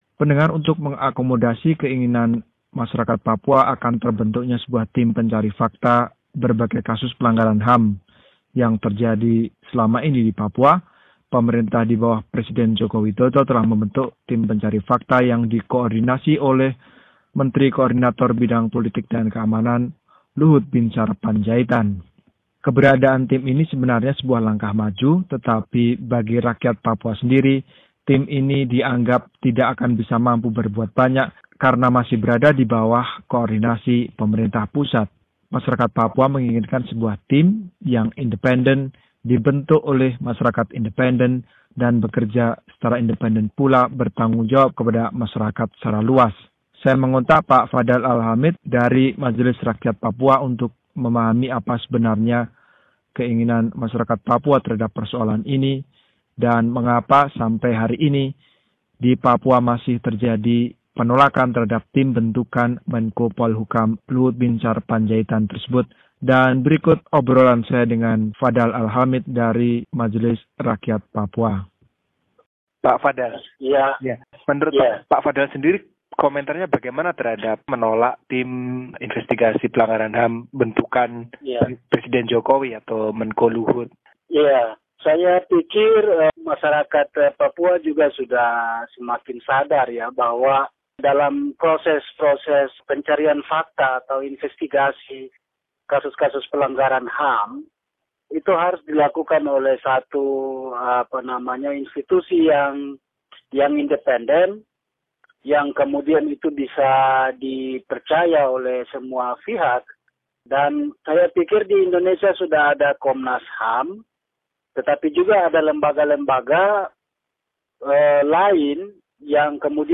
Sebuah wawancara